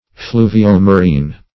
Search Result for " fluvio-marine" : The Collaborative International Dictionary of English v.0.48: Fluvio-marine \Flu`vi*o-ma*rine"\, a. [L. fluvius river + E. marine.]